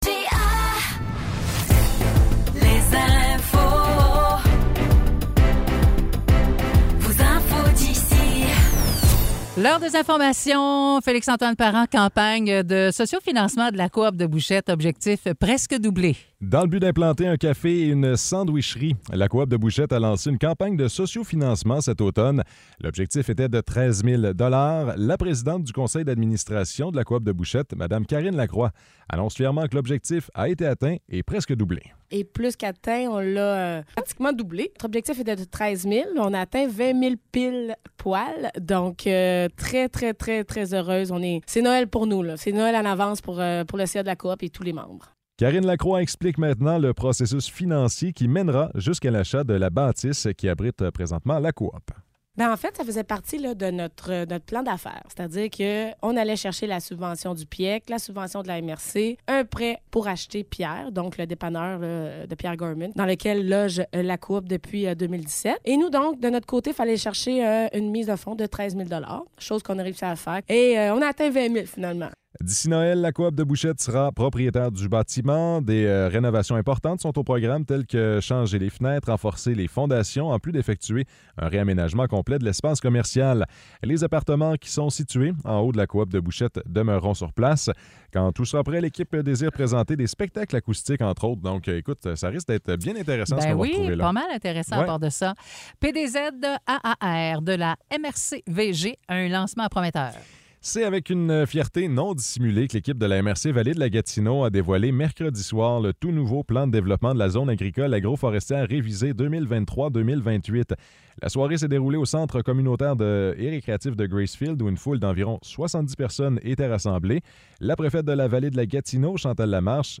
Nouvelles locales - 23 novembre 2023 - 10 h